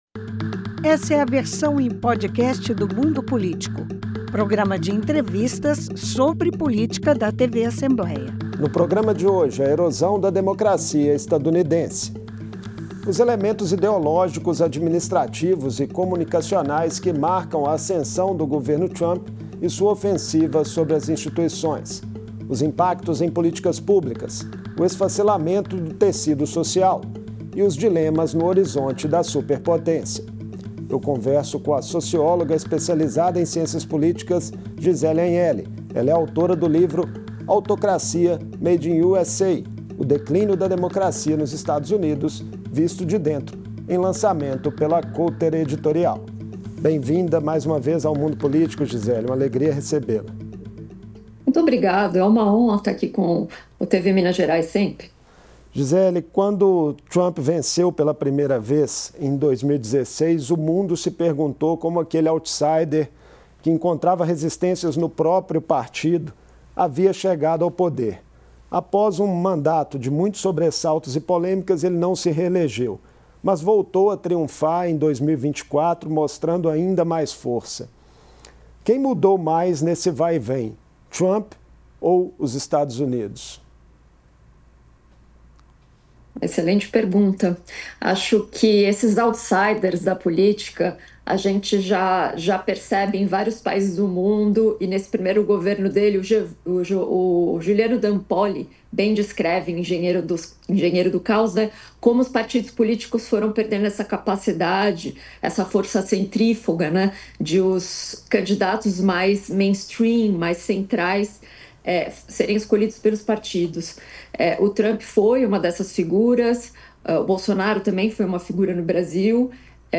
Em entrevista ao Mundo Político, ela comenta a corrosão das instituições democráticas estadunidenses, com um legislativo inoperante e um judiciário conivente.